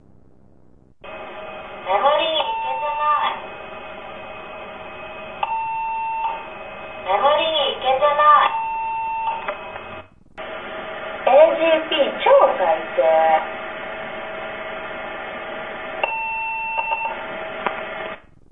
ギャル？の声をお断り無く拝借してしまいました。
でも思ったより低い声の方ですね・・・。